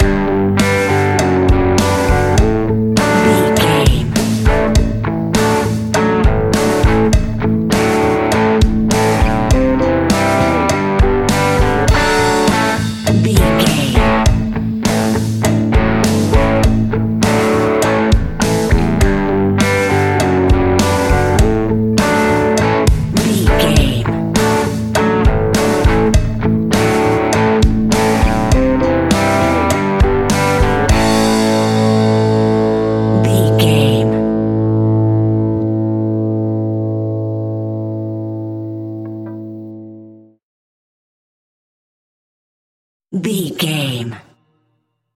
Epic / Action
Fast paced
Mixolydian
hard rock
heavy metal
blues rock
distortion
rock guitars
Rock Bass
Rock Drums
heavy drums
distorted guitars
hammond organ